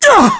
Kasel-Vox_Damage_kr_01.wav